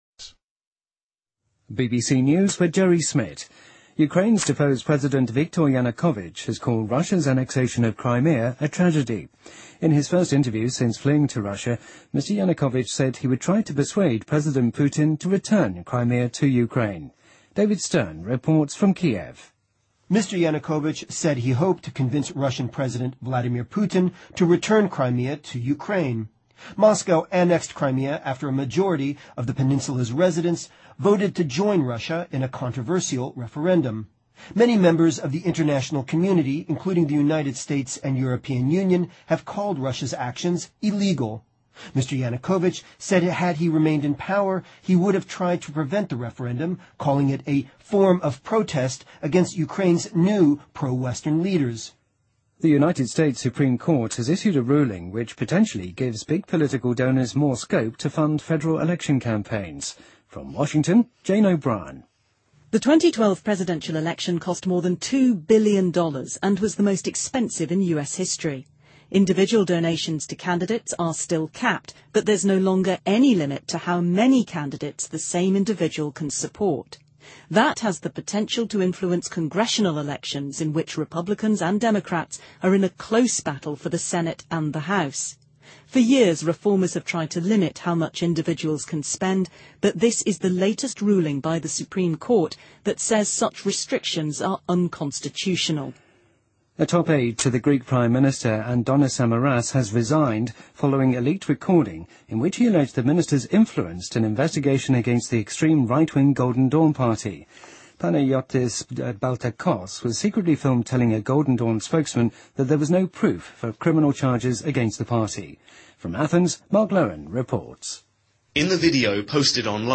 BBC news,2014-04-03